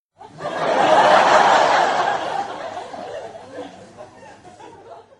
Risada Sitcom
Risada típica e curta do público de uma sitcom (seriado cômico).
risada-sitcom.mp3